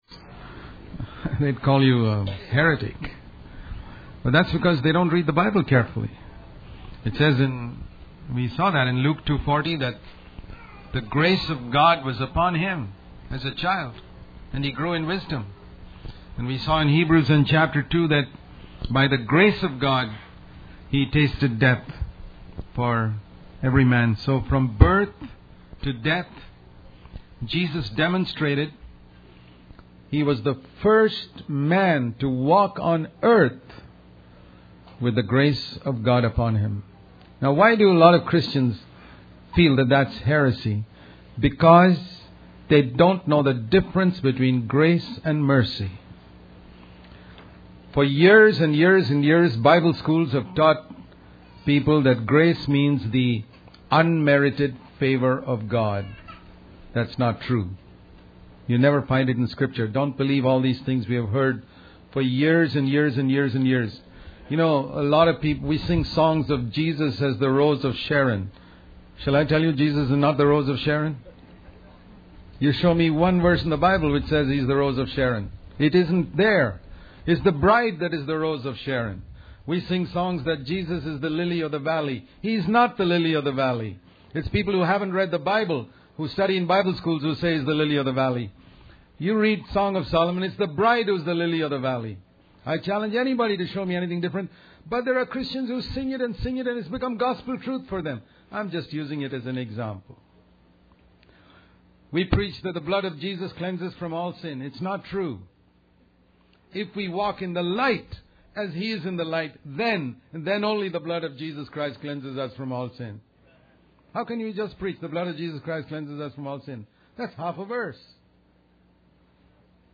In this sermon, the speaker emphasizes the importance of living out the teachings of Jesus rather than just preaching them. He encourages believers to be honest about their own sins and not to blame others.